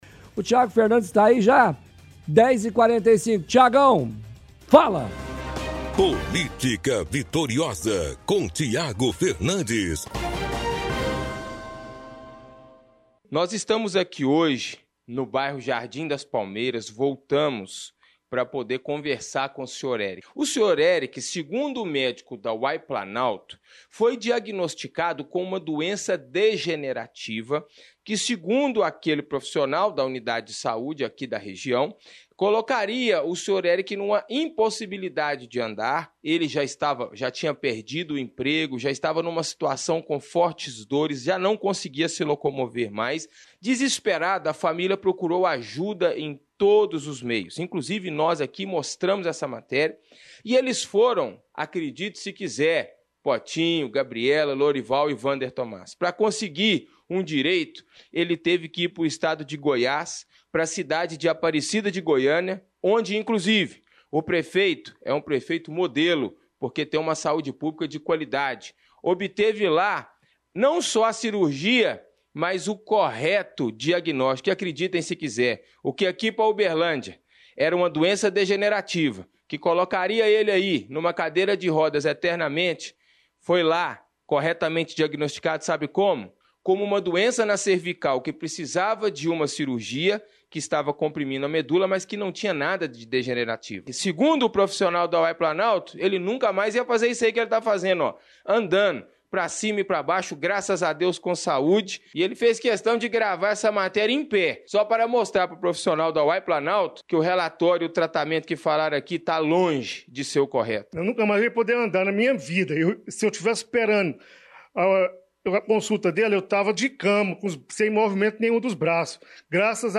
– Transmissão de áudio da reportagem de hoje do Chumbo Grosso.